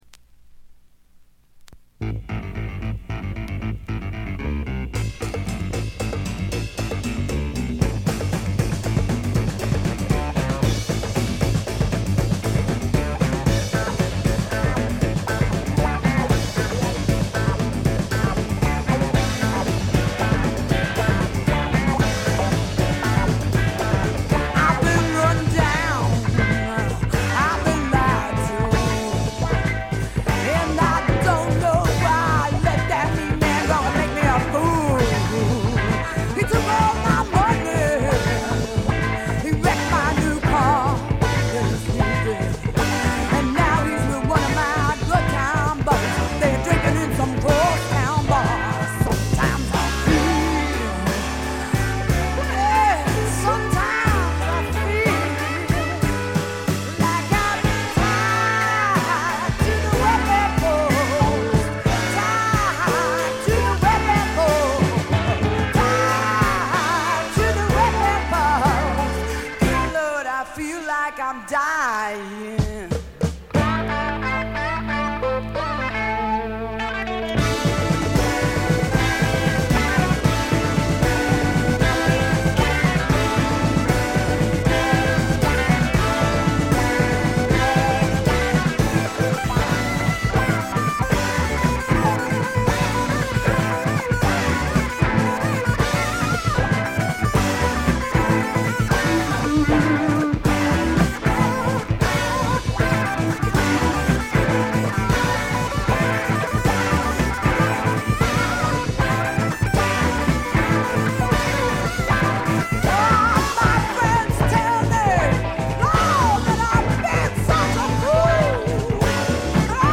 部分試聴ですがほとんどノイズ感無し。
ファンキーでタイト、全編でごきげんな演奏を繰り広げます。
試聴曲は現品からの取り込み音源です。